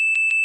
generic_fail_loud.wav